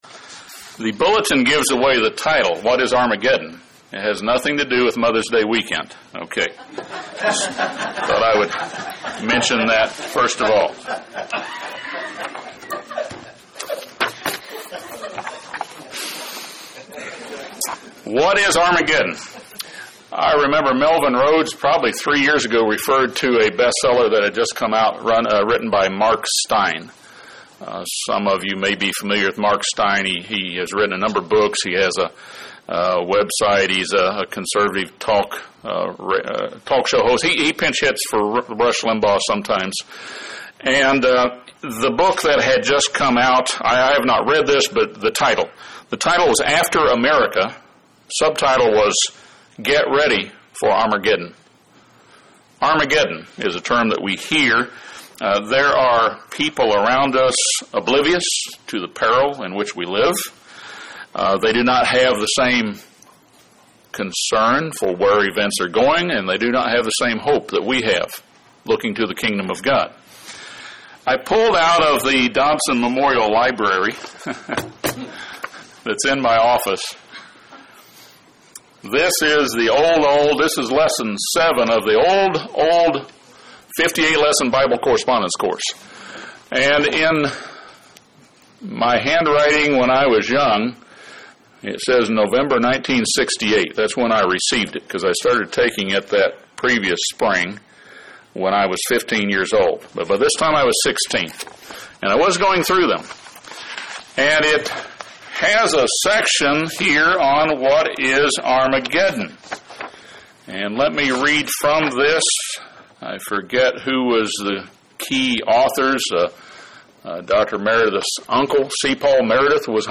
It refers to a gathering place for armies but not to a battle. This sermon discusses what Armageddon is not, what it is and then offers an overview of the end-time events leading up to the return of Jesus Christ as King of kings.